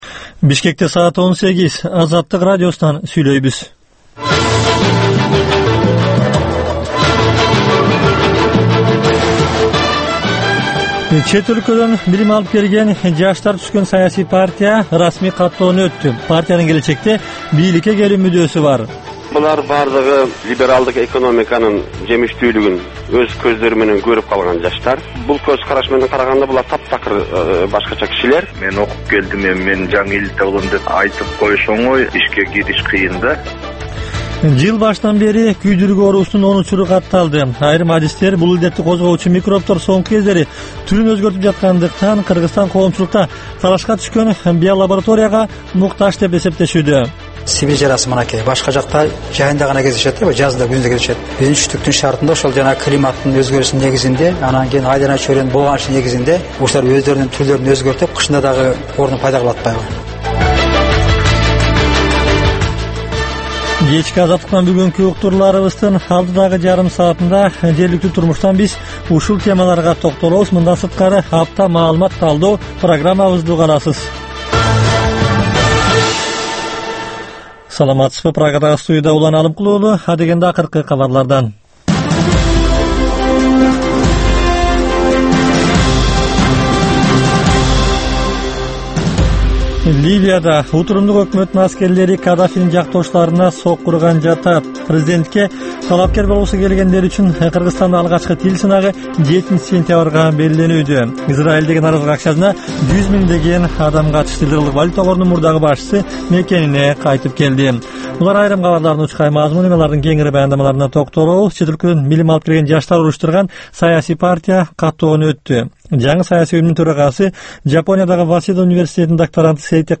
Кечки 6дагы кабарлар